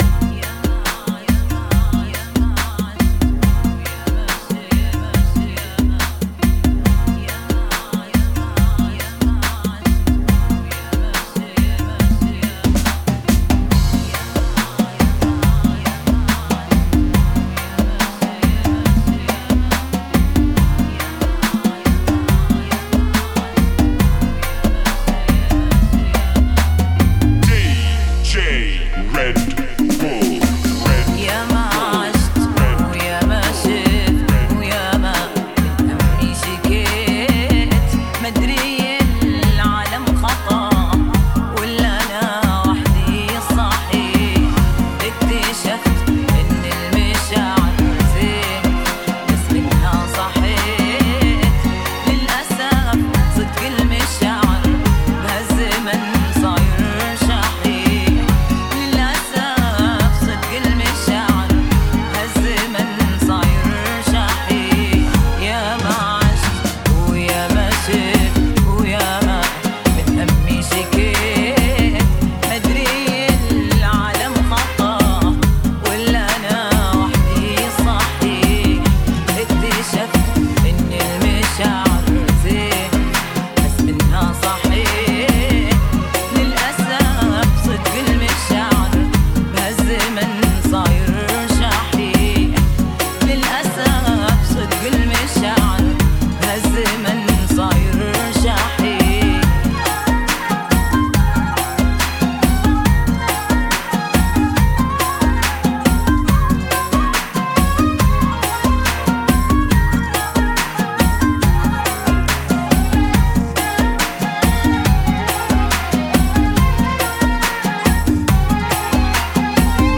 [ 70 bpm ]